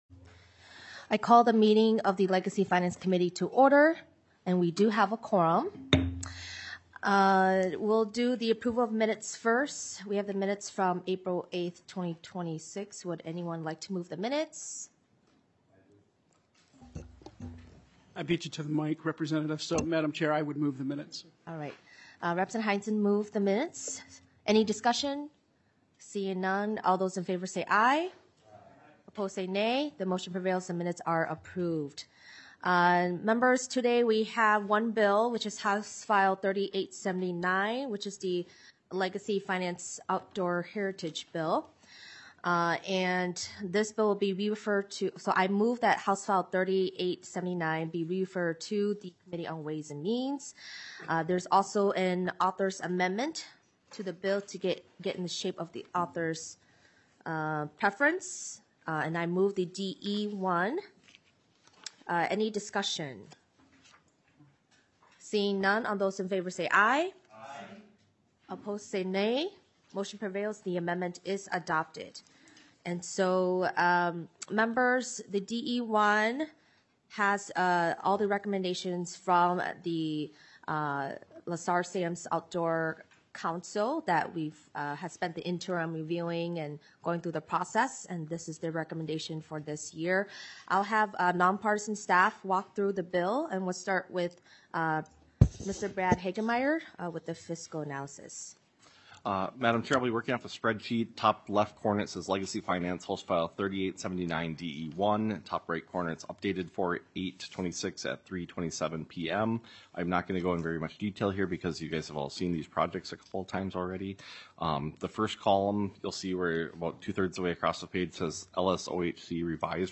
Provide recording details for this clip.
* Each bill will be heard informationally. * Each bill presentation will have three minutes to present and four minutes for questions.